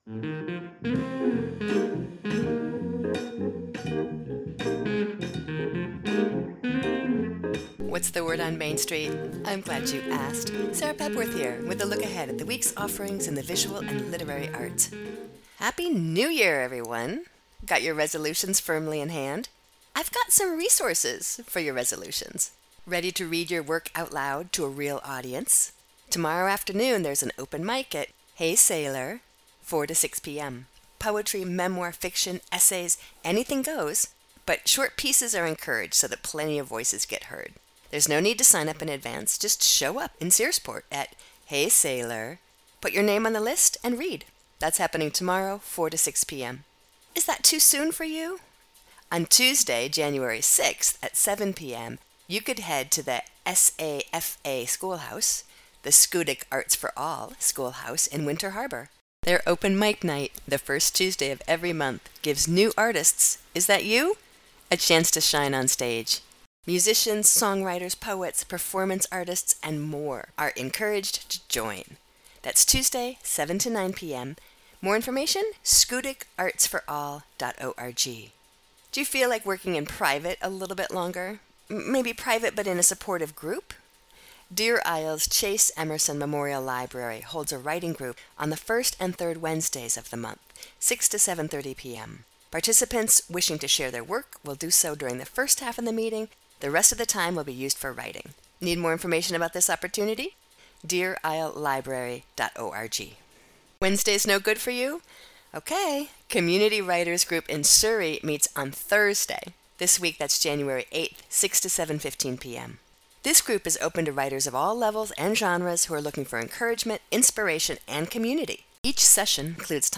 is a weekly short feature Saturdays at 9:30am looking at local literary and visual arts events and offerings!
Infinite Blues is a cut from his recently released neon night, an excursion into an ambient/electronic musical world built around rhythmic bass ostinatos, clouds of processed looping electronic atmospheres, and melody. By turns both subtle and unapologetically noisy, the songs are a collection of luminous constellations, roved between by a band of texturally minded instrumental improvisers.